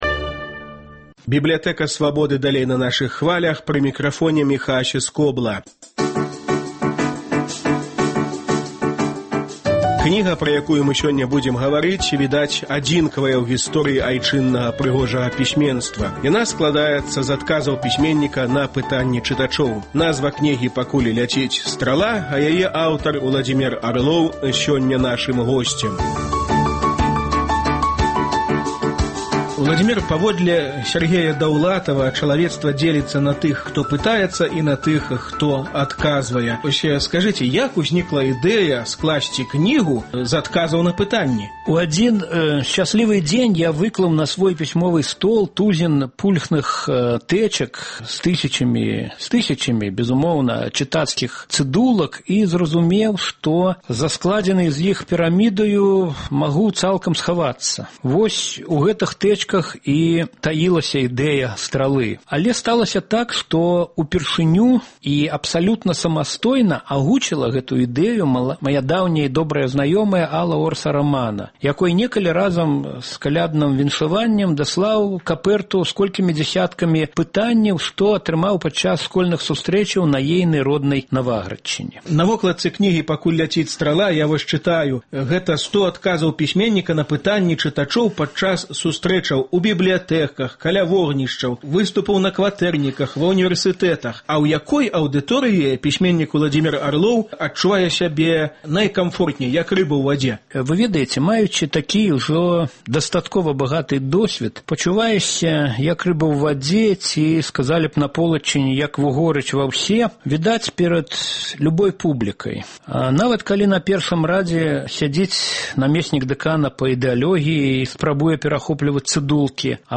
Працяг радыёсэрыі “10 гадоў “Бібліятэкі Свабоды”. Гутарка з Уладзімерам Арловым пра кнігу «Пакуль ляціць страла».